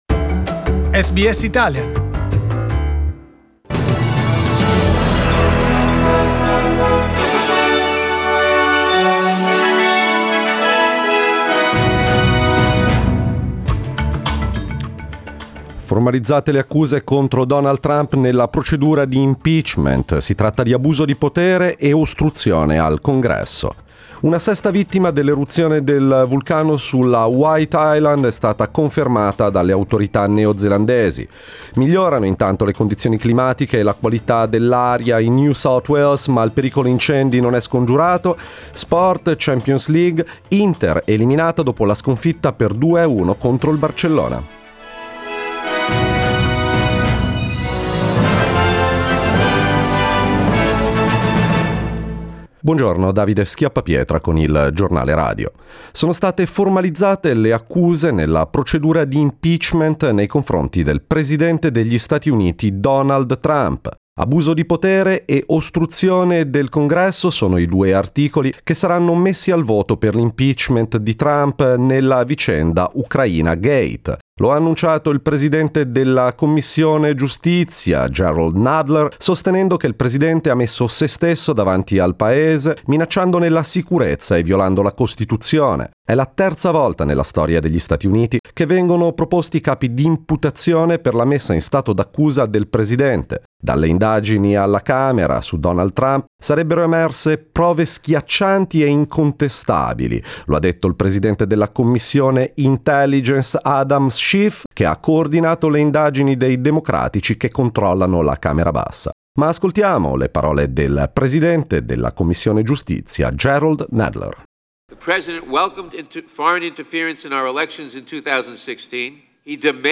Giornale radio mercoledì 11 dicembre
SBS Italian news bulletin Source: Pexels